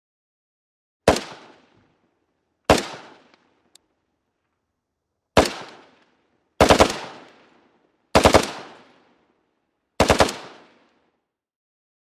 AutoMachGunBurst PE706103
WEAPONS - MACHINE GUNS HEAVY SQUAD AUTOMATIC WEAPON: EXT: Short bursts & single shots with echo.